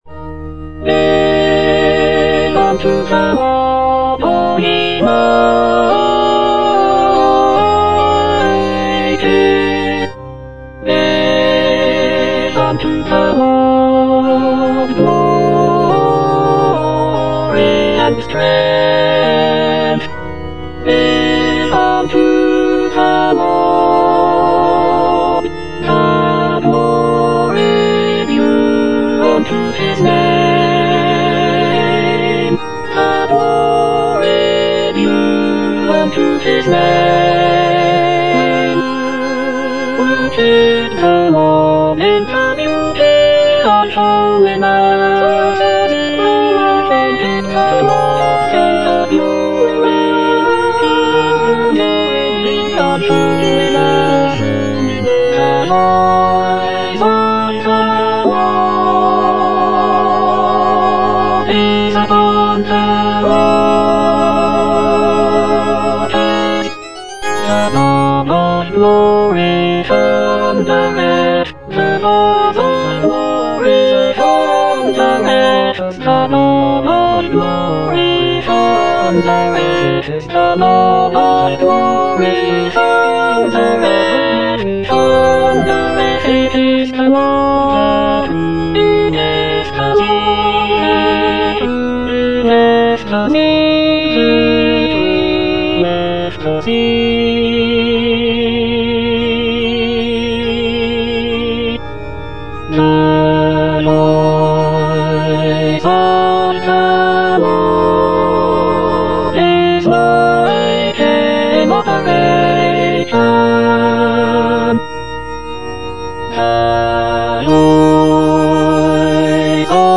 E. ELGAR - GIVE UNTO THE LORD Alto I (Emphasised voice and other voices) Ads stop: auto-stop
"Give unto the Lord" is a sacred choral work composed by Edward Elgar in 1914. It is a powerful and uplifting piece that showcases Elgar's mastery of choral writing and his ability to create rich harmonies and intricate textures. The work is set for double chorus and orchestra, and features a majestic and triumphant sound that is characteristic of Elgar's music.